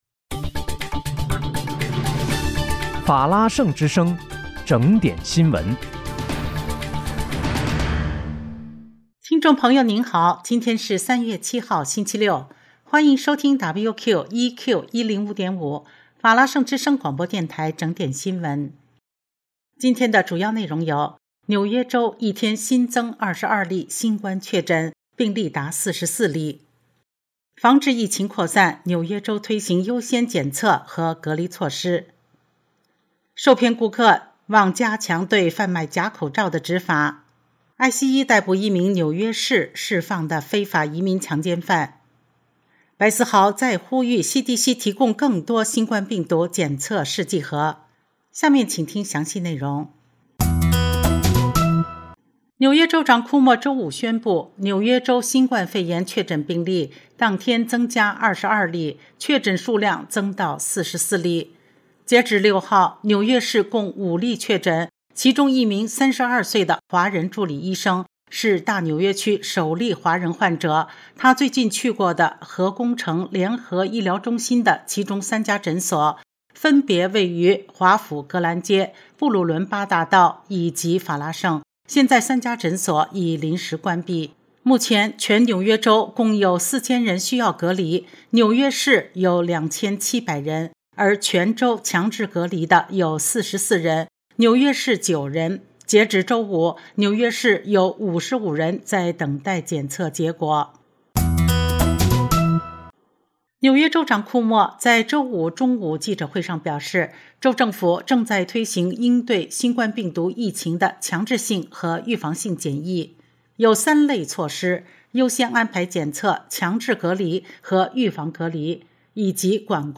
3月7日（星期六）纽约整点新闻
听众朋友您好！今天是3月7号，星期六，欢迎收听WQEQ105.5法拉盛之声广播电台整点新闻。